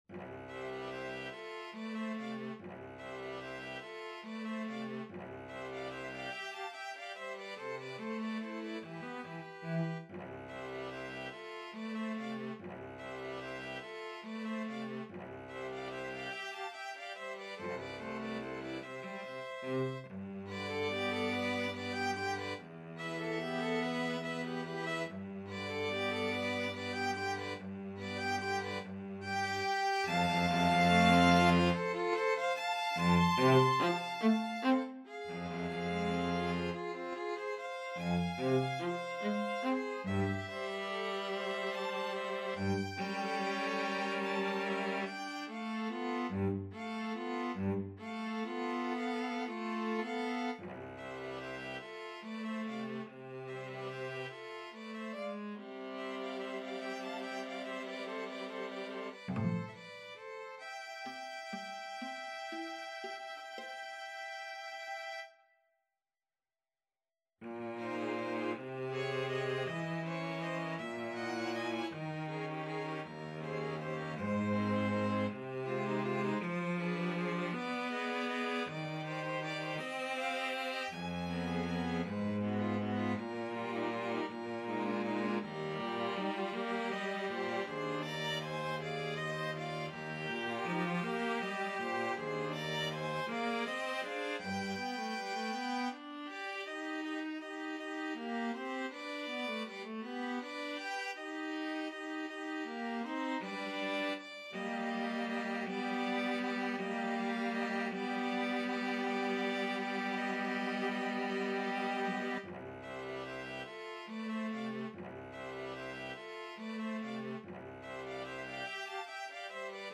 Free Sheet music for String Quartet
Violin 1Violin 2ViolaCello
C major (Sounding Pitch) (View more C major Music for String Quartet )
Gently rocking = 144
6/8 (View more 6/8 Music)
Classical (View more Classical String Quartet Music)